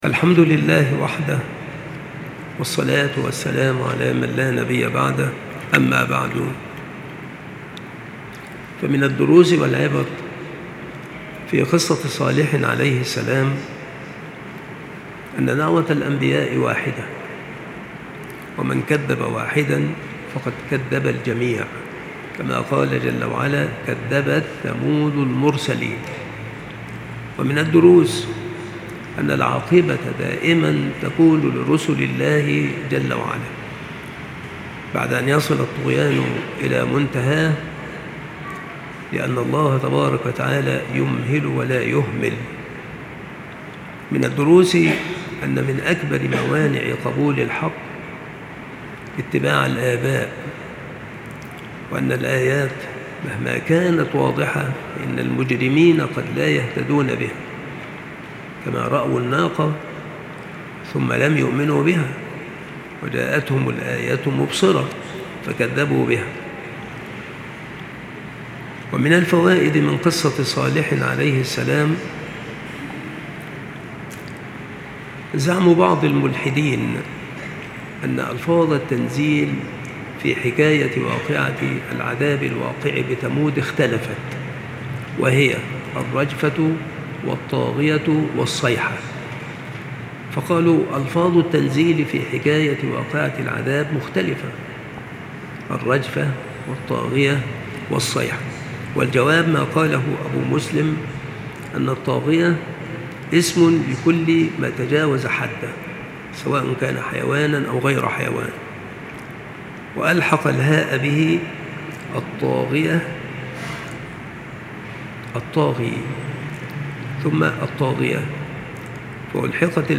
التصنيف : قصص الأنبياء
• مكان إلقاء هذه المحاضرة : بالمسجد الشرقي - سبك الأحد - أشمون - محافظة المنوفية - مصر